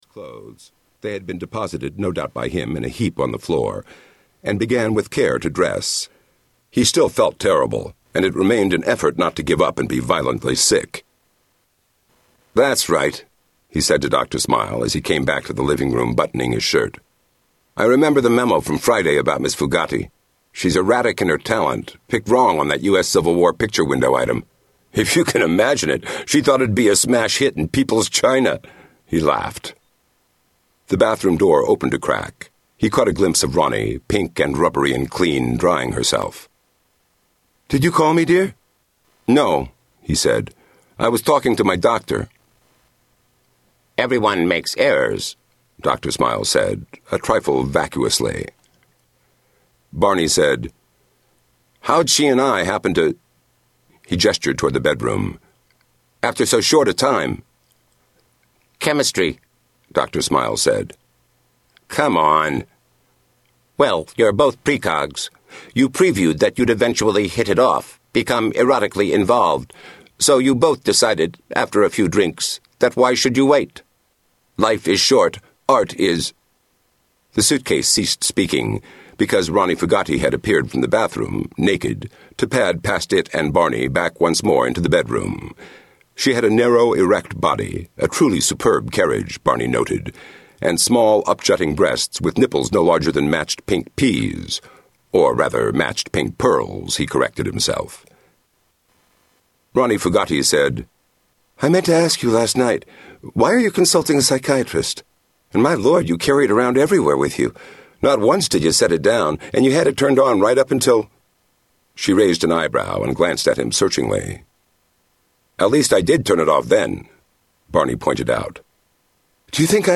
Tags: Philip K Dick Audiobooks Philip K Dick Philip K Dick Audio books Scie-Fi Scie-Fi books